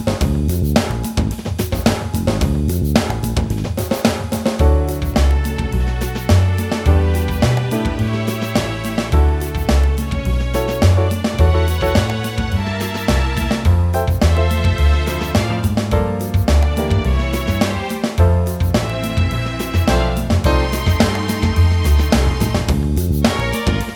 With Intro Jazz / Swing 3:28 Buy £1.50